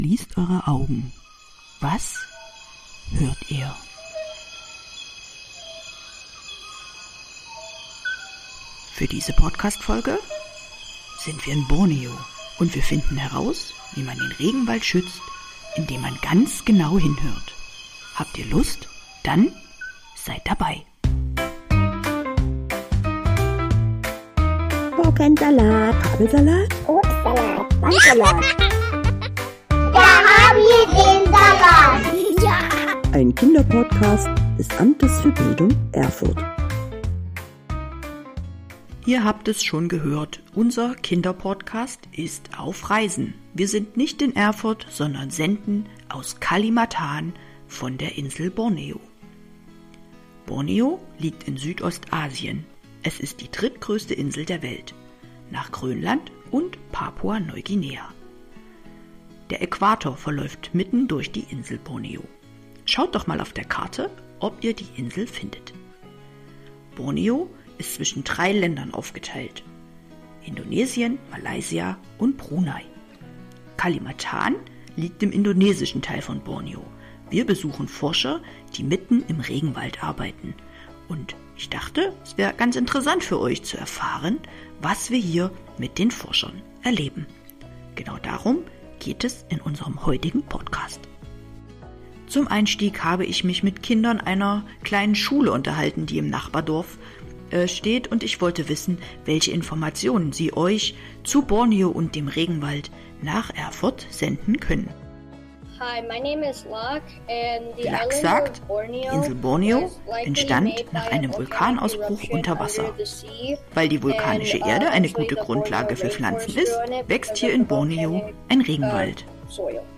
Wir begleiten Forscher im Regenwald von Borneo, die mit Mikrofonen herausfinden, welche Tiere dort leben. Dabei lernen wir, wie man die Geräusche unterscheiden kann und wie einige Tiere aus Borneo klingen.